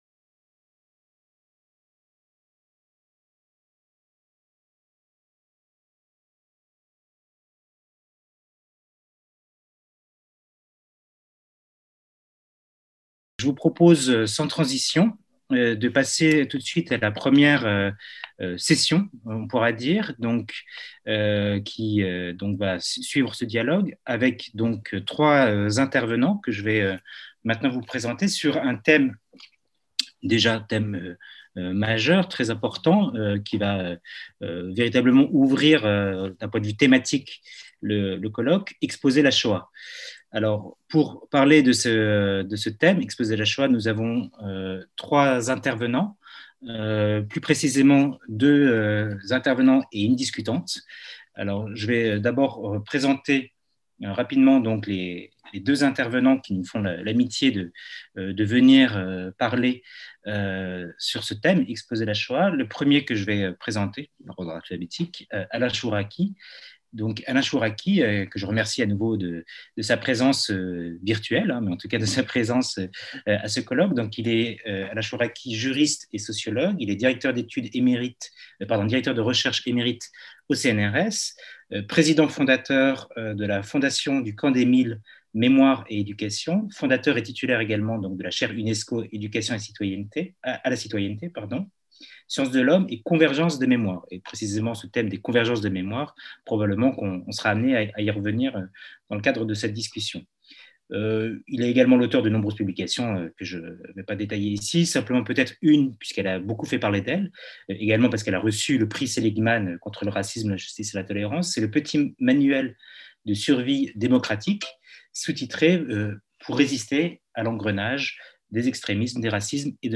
Colloque | 6 mai Le Musée national de l’histoire de l’immigration et la Plateforme internationale sur le Racisme et l'Antisémitisme PIRA (FMSH,EPHE) vous proposent un colloque international autour des traitements du racisme et de l'antisémitisme dans les expositions muséales.